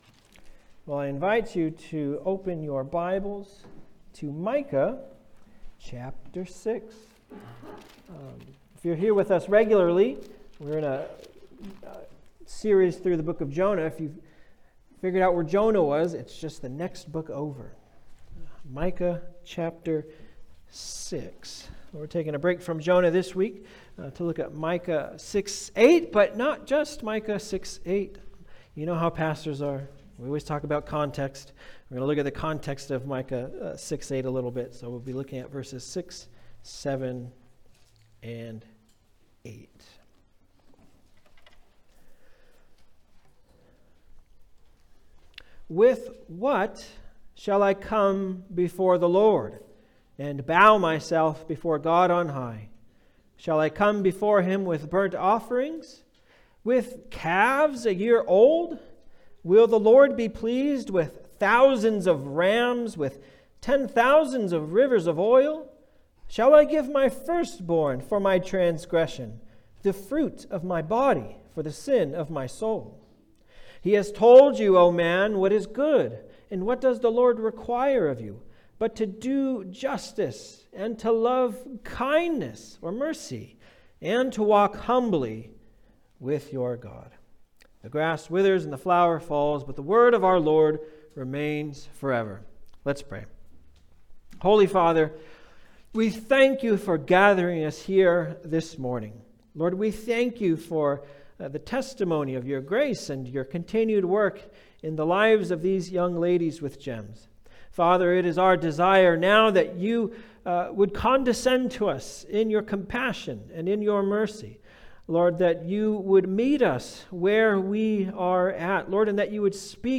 Passage: Micah 6:6-8 Service Type: Sunday Service « The Fleeing Prophet The Sailors